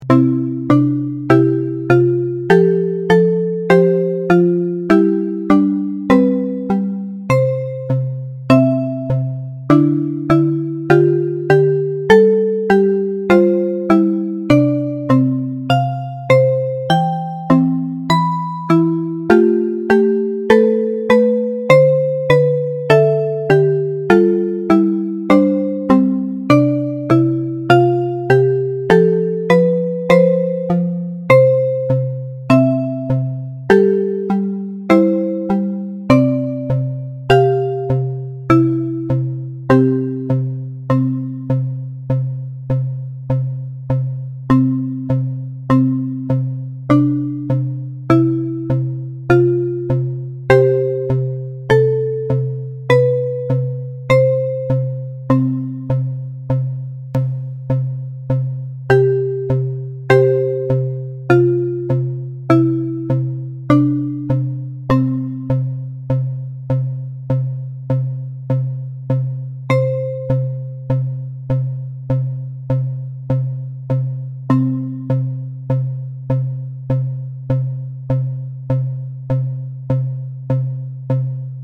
明るいのイメージです。ループ対応。
BPM100
ゆっくり